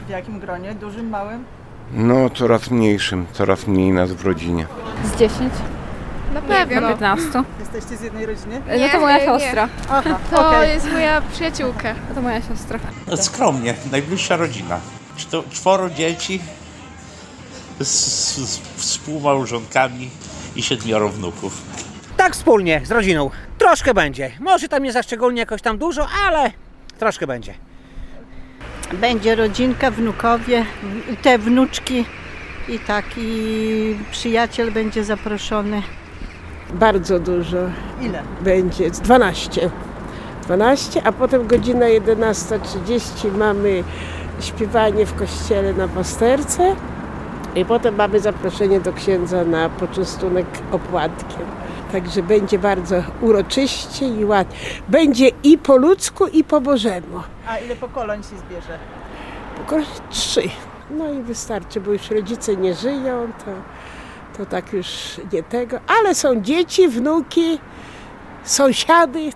Boże Narodzenie w rodzinnym gronie: takie są plany suwalczan, którzy podzielili się nimi z Radiem 5.